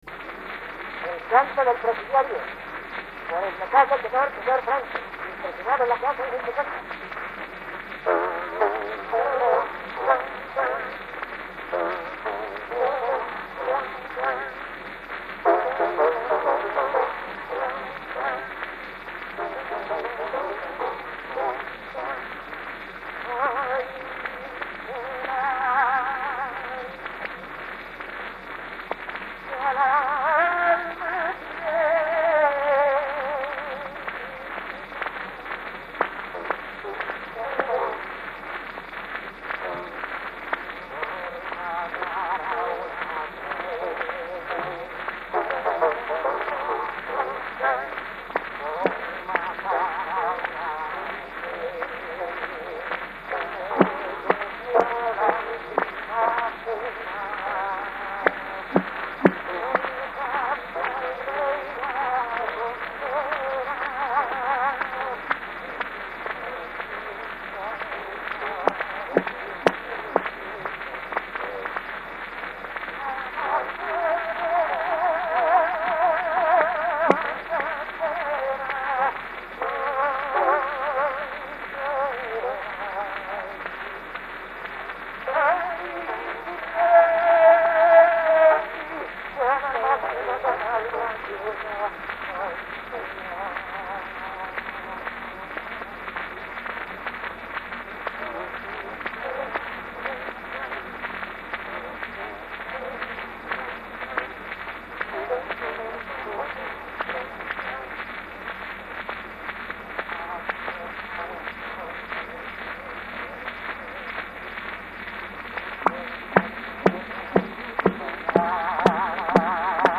Hugens y Acosta cylinder (unnumbered), Madrid, between 1898 and 1901
Franco Cardinali sings
The sound quality, both as far as original recording and as far as cylinder wear, leaves a whole lot to be desired; but what can be clearly discerned is a highly individual, almost unique timbre. Weightless, exceptionally bright, yet by no means unmanly, with a very weak low register and a not-too-easy top, it's a short voice, but imbued with a haunting, bittersweet melancholic quality: what the voice is lacking in compass, the singer makes up for with the extraordinarily distinctive sound that he produces, and with outstanding musicality.